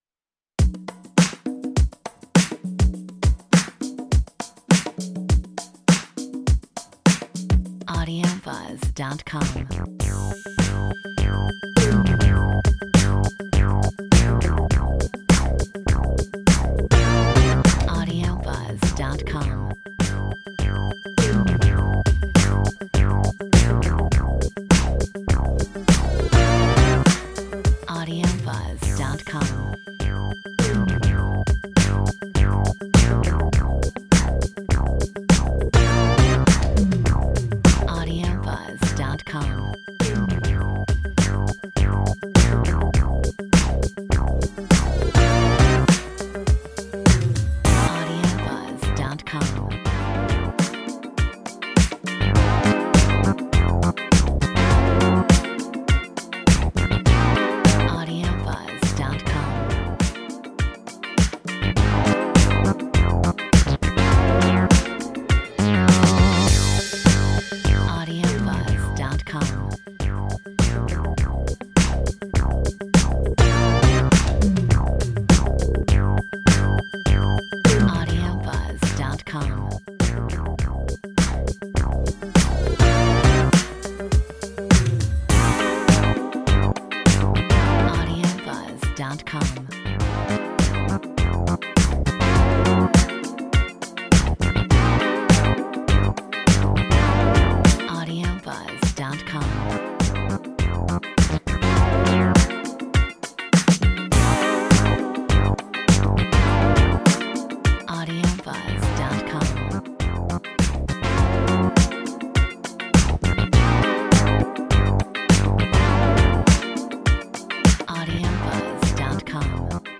Metronome 102
A fun, early 80s inspired synth pop track with funk guitar